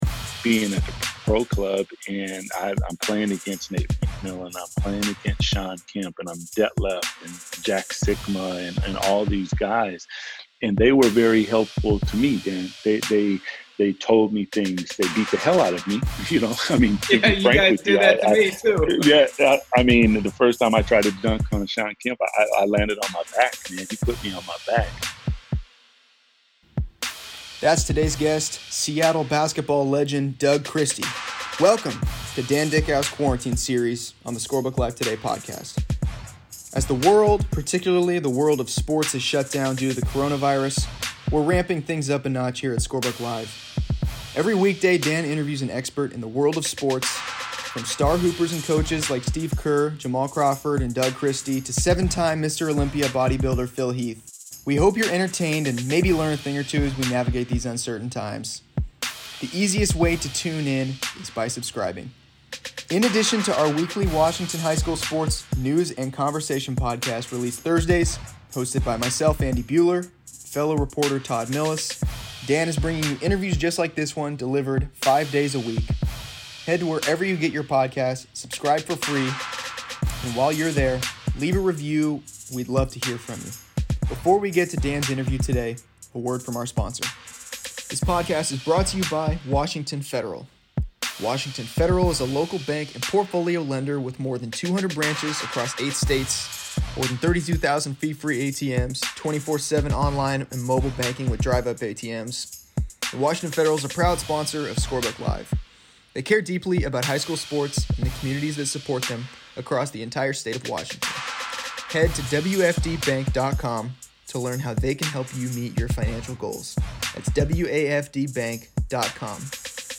Each weekday, Dickau releases an interview with a wide range of experts in the world of sports. On Friday, 15-year NBA veteran and Seattle basketball legend Doug Christie joins.